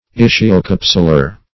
Search Result for " ischiocapsular" : The Collaborative International Dictionary of English v.0.48: Ischiocapsular \Is`chi*o*cap"su*lar\ (?; 135) a. [Ischium + capsular.]
ischiocapsular.mp3